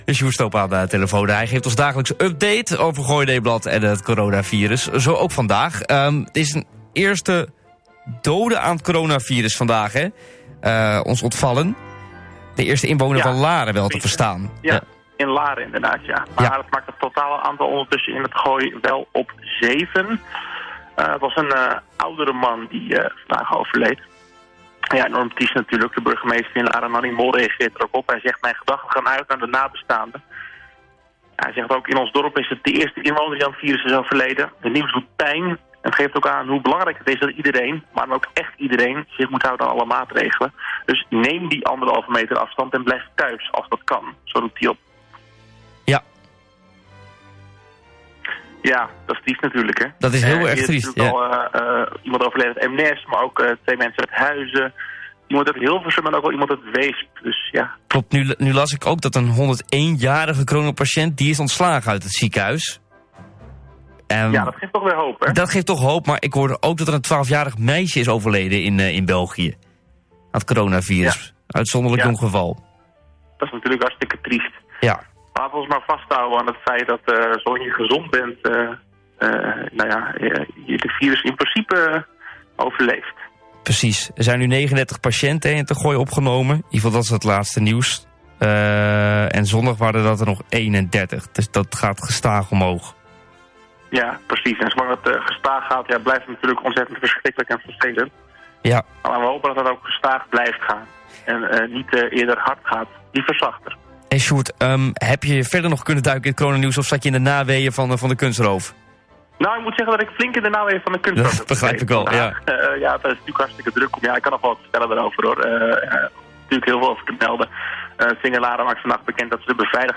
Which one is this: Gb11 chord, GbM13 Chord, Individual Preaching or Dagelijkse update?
Dagelijkse update